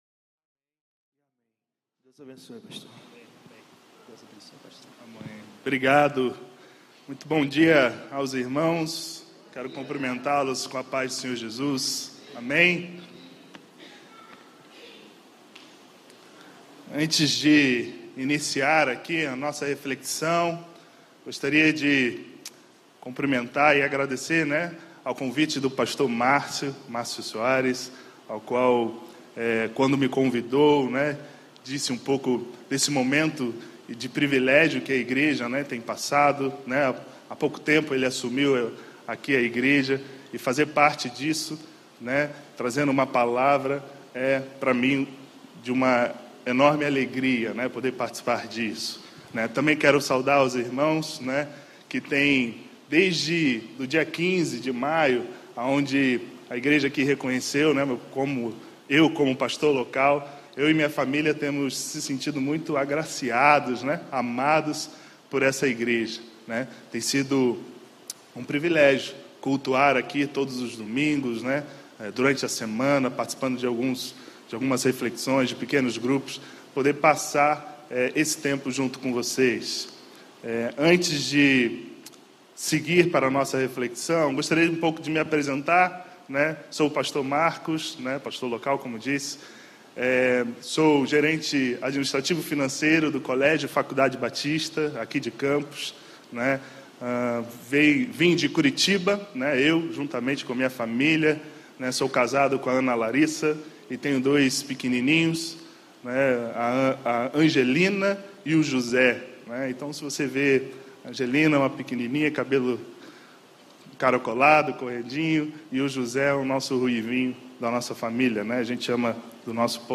Mensagem
na Primeira Igreja Batista do IPS.